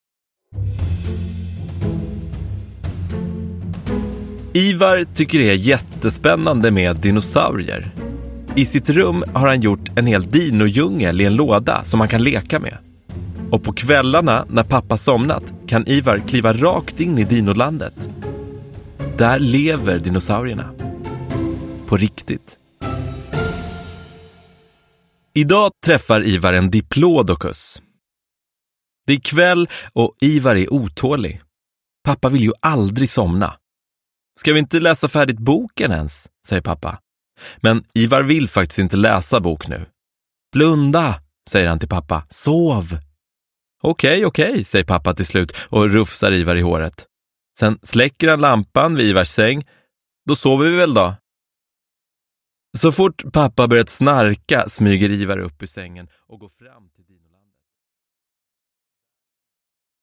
Ivar träffar en diplodocus – Ljudbok – Laddas ner